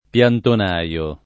vai all'elenco alfabetico delle voci ingrandisci il carattere 100% rimpicciolisci il carattere stampa invia tramite posta elettronica codividi su Facebook piantonaio [ p L anton #L o ] (meno com. piantinaio ) s. m. (agr.); pl.